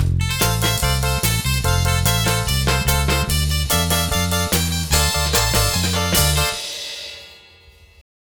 Twisting 2Nite 5 Full-E.wav